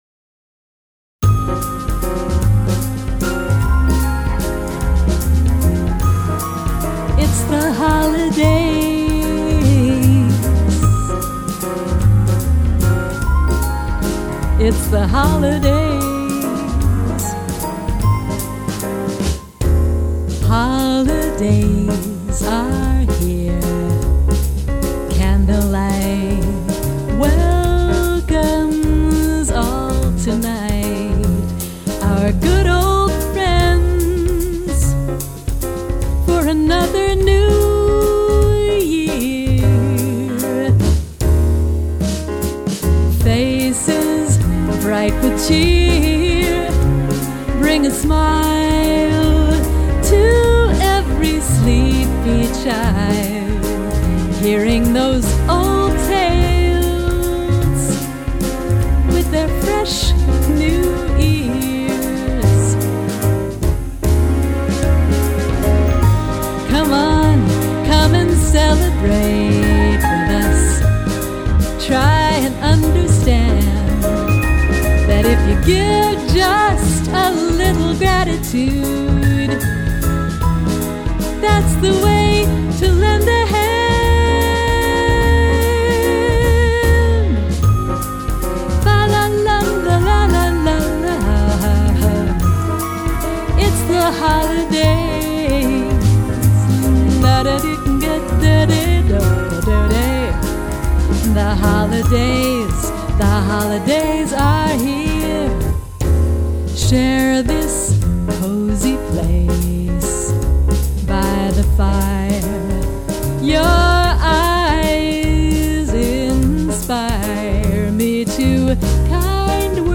waltz
vocals